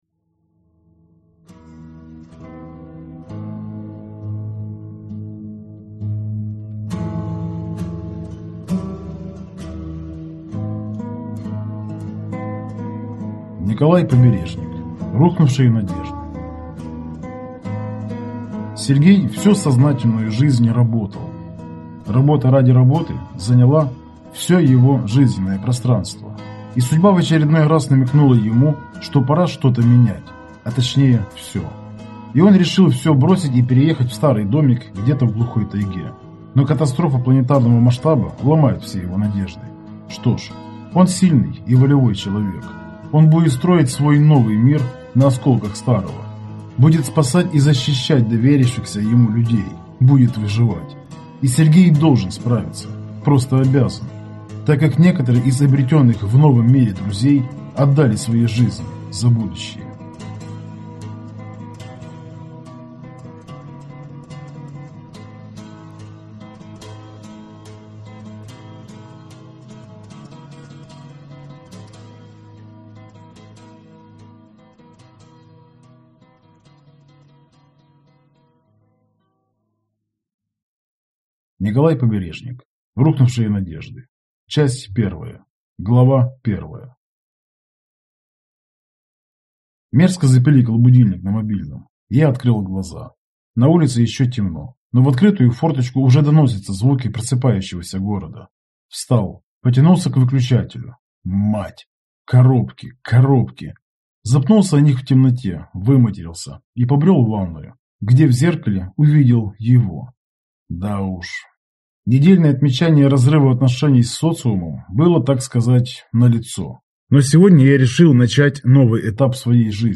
Аудиокнига Рухнувшие надежды | Библиотека аудиокниг